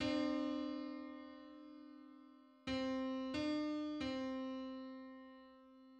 Just: 589/512 = 242.55 cents.
Public domain Public domain false false This media depicts a musical interval outside of a specific musical context.
Five-hundred-eighty-ninth_harmonic_on_C.mid.mp3